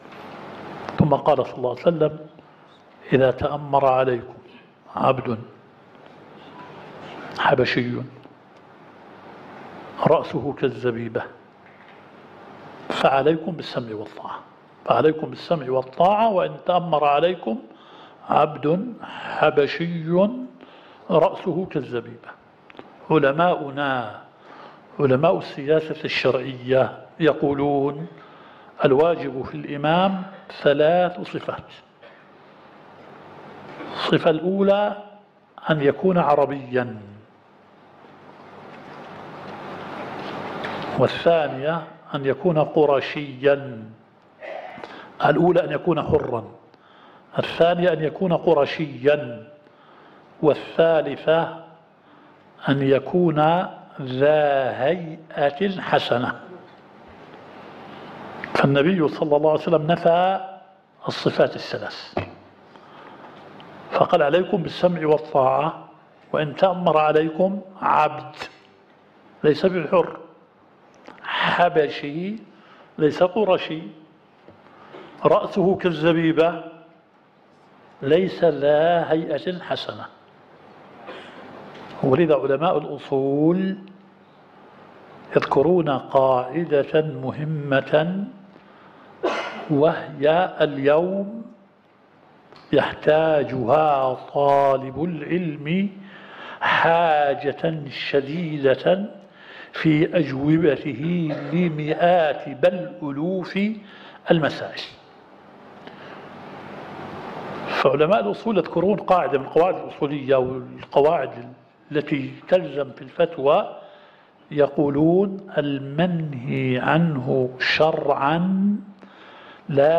الدورة الشرعية الثالثة للدعاة في اندونيسيا – منهج السلف في التعامل مع الفتن – المحاضرة الأولى.